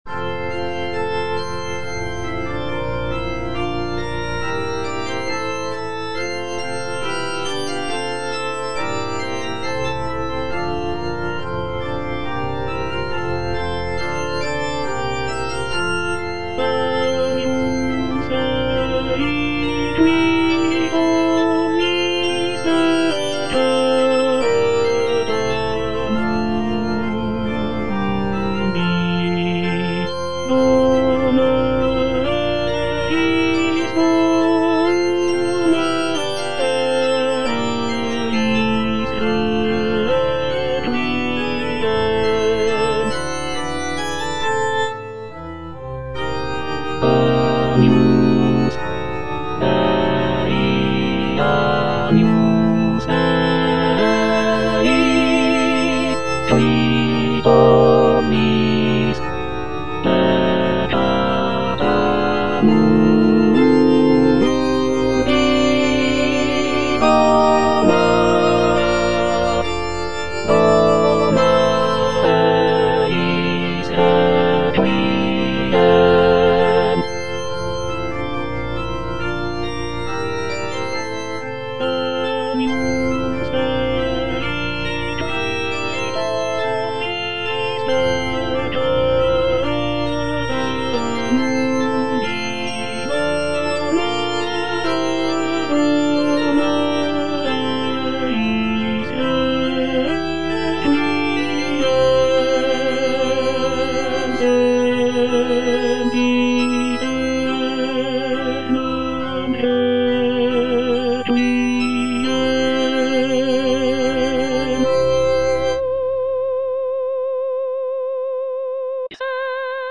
VERSION WITH A SMALLER ORCHESTRA
All voices